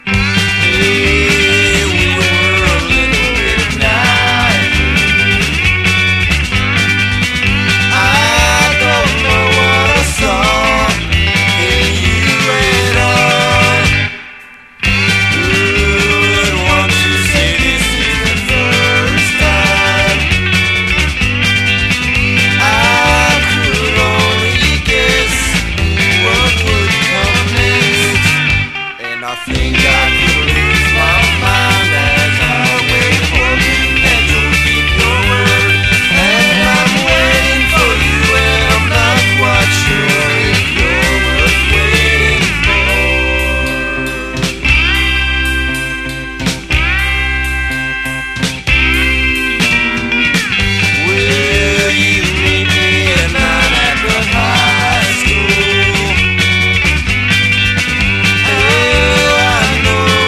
NEO ROCKABILLY
70'Sロカビリー！
エキセントリックなヴォーカルも◎なパーティ・ロカビリー・ナンバー揃いです。